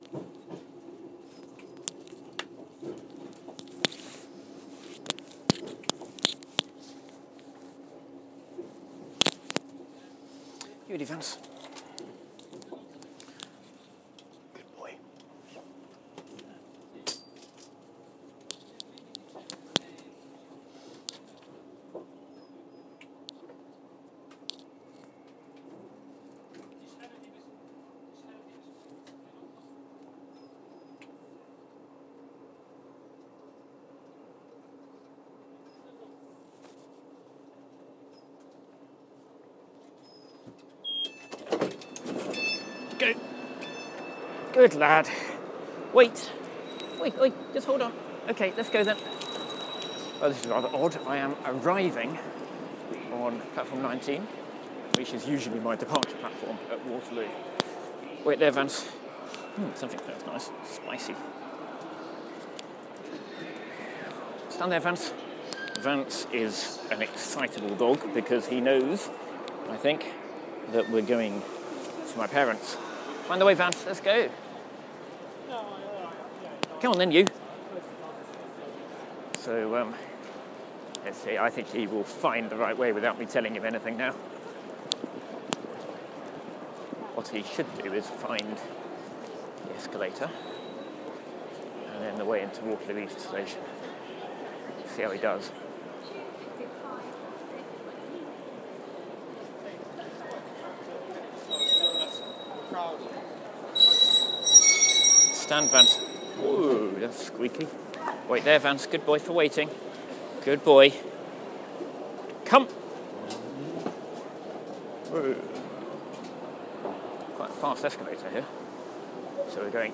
I'm sure that many of the people I come across whilst travelling around London think that I simply tell Vance the address of the place I want to visit and off he goes, as if he possesses the latest in canine satellite navigation. Well, on this occasion Vance excels himself, showing off not only his biological compass but also his powers of telepathy, changing trains at Waterloo with barely an instruction from me.